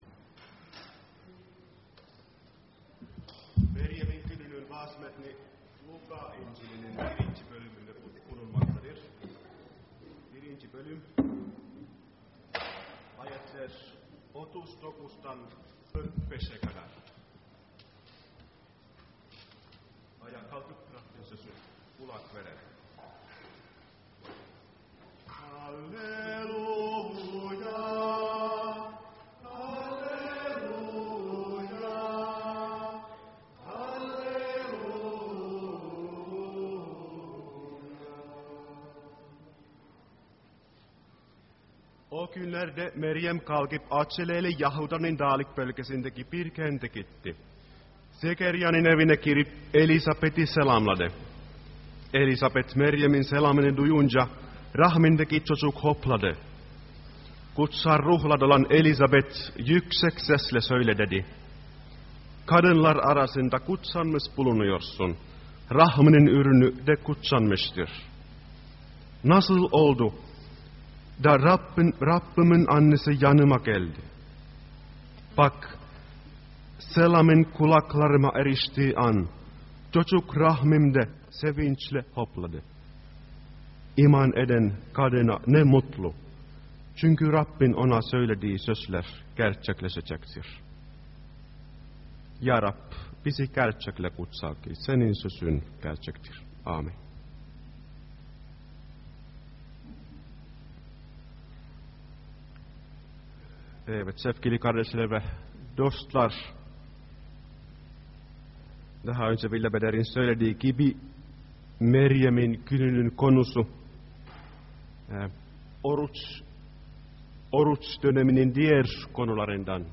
2014 yılı: Luka Kitabından vaazlar